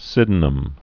(sĭdn-əm), Thomas 1624-1689.